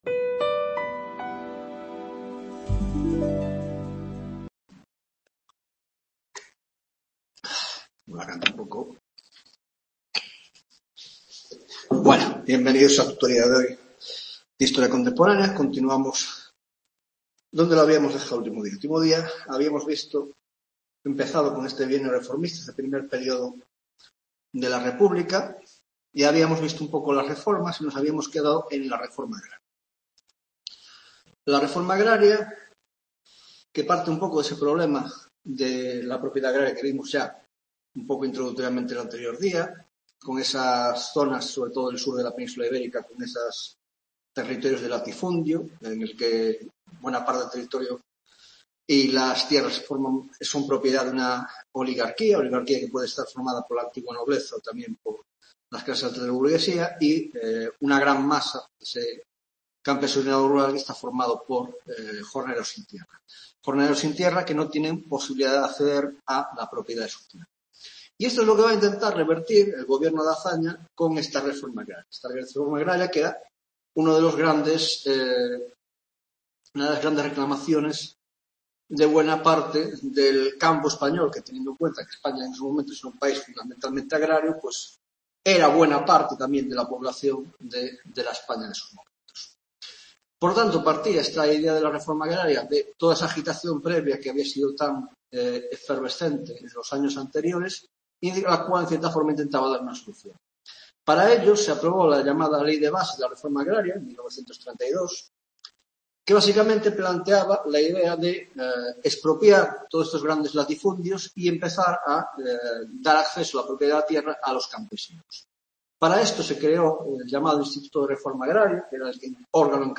22ª tutoría de Historia Contemporánea